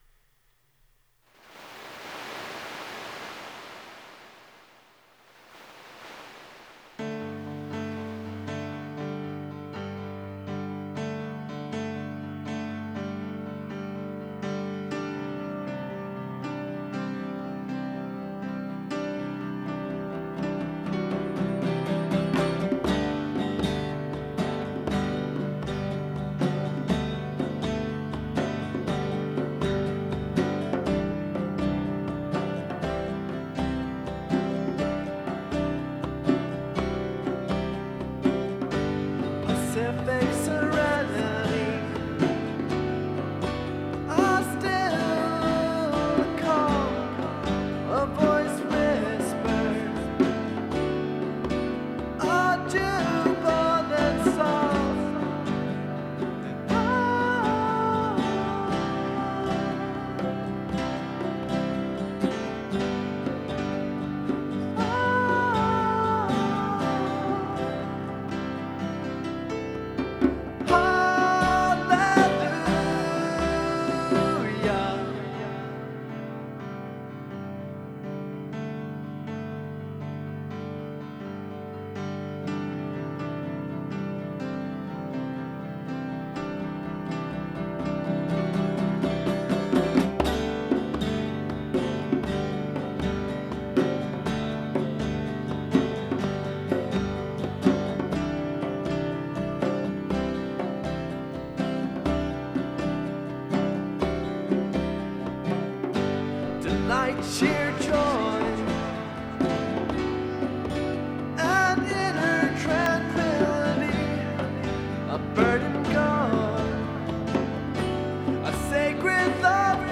Genre(s): Pop
Instrumentation: Guitar, Piano, Strings, Vocals